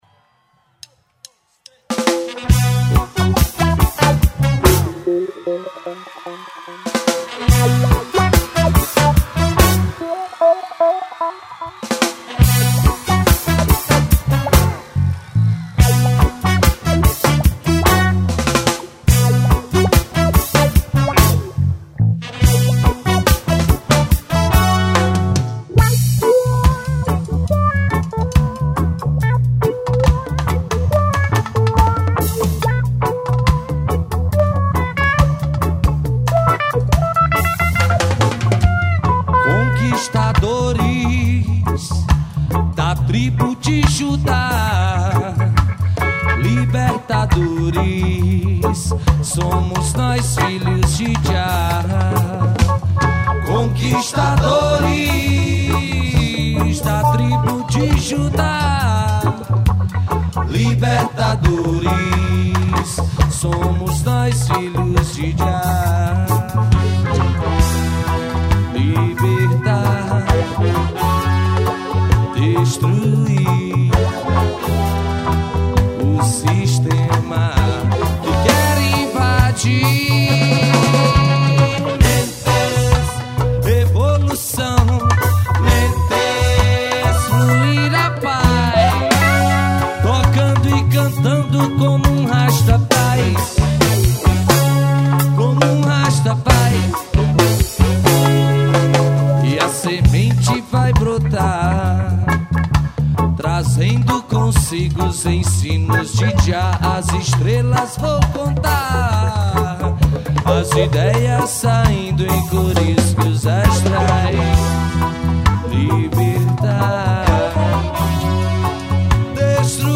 AO VIVO
2123   03:23:00   Faixa:     Reggae
Guitarra, Voz
Escaleta, Teclados
Bateria
Baixo Elétrico 6
Trompete, Vocal
Sax Alto